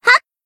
BA_V_Yukari_Battle_Shout_1.ogg